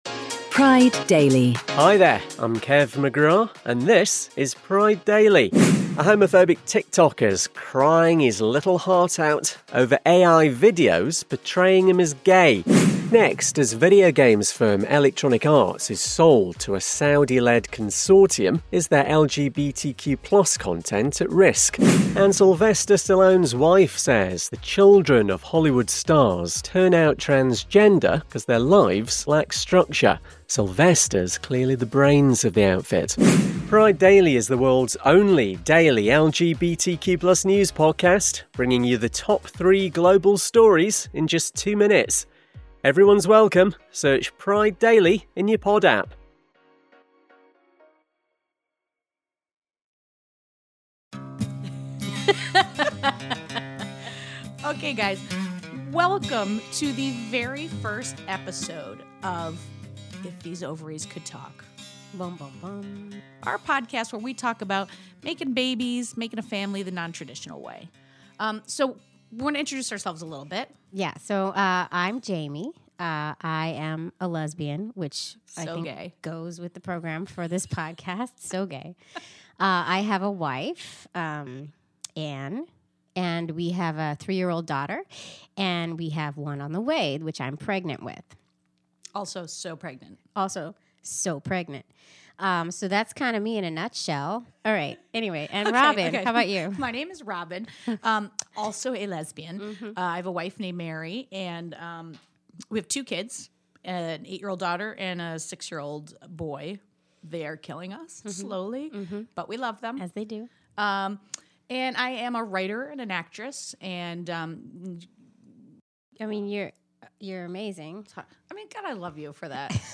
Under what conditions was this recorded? This is an archival re-release of the episode that started it all, capturing the very beginning: pregnant, overwhelmed, hopeful, and figuring it out as they went. Audio quality and language reflect the time it was recorded.